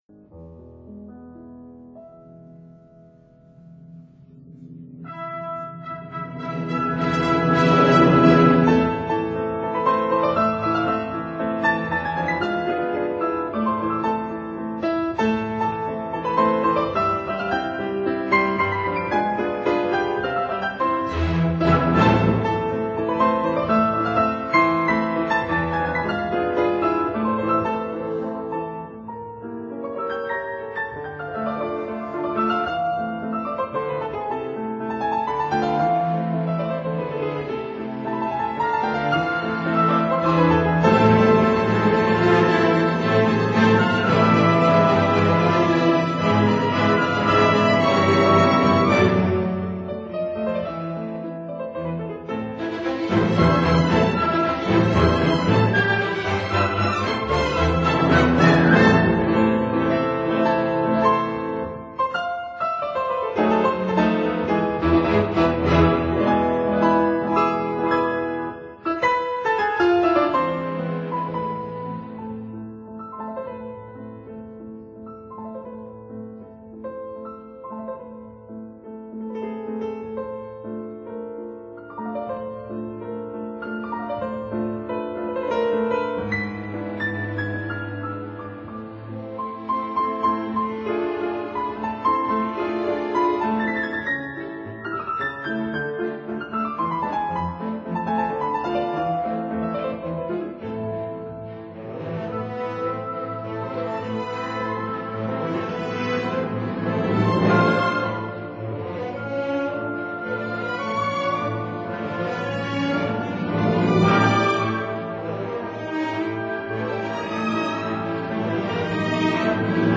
piano
violin